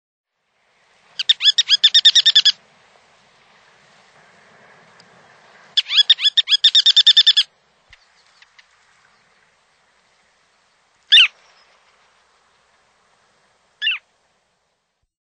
Ruddy Turnstone
Bird Sound
A short rattling chuckle or twittering.
RuddyTurnstone.mp3